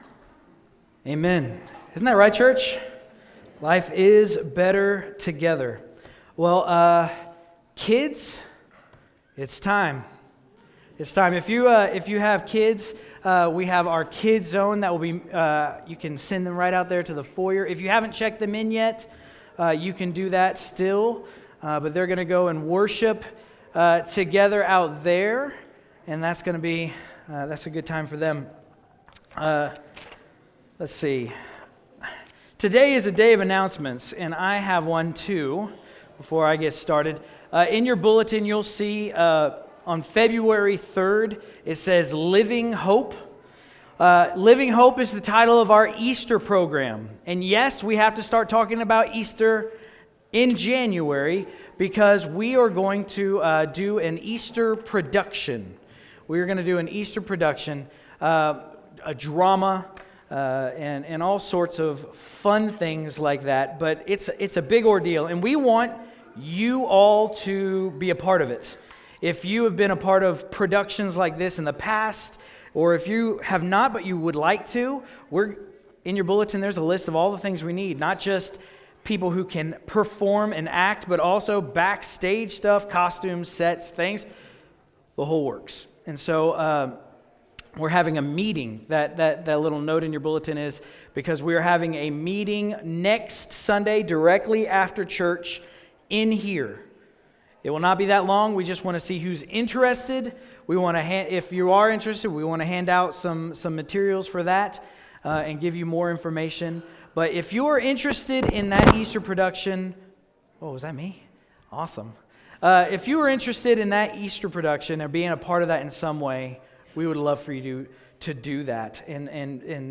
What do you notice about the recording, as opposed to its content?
Passage: Romans 12:3-8 Service Type: Sunday Service